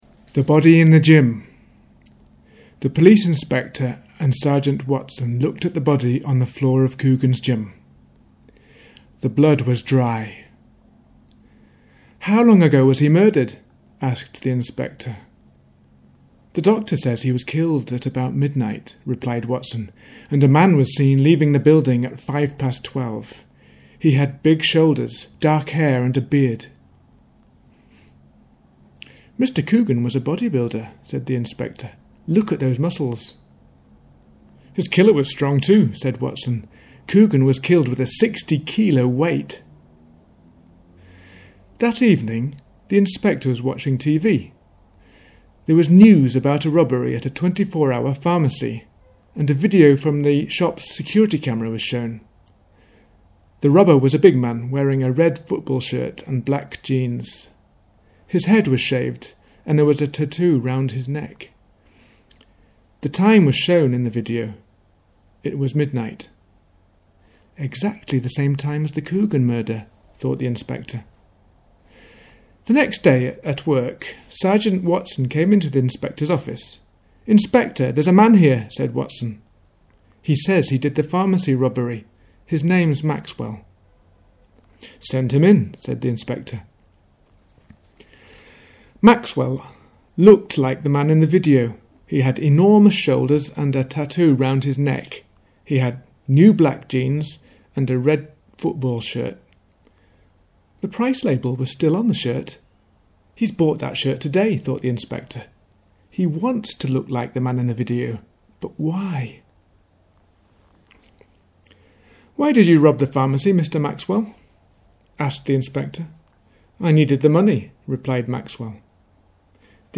Body in the Gym story.mp3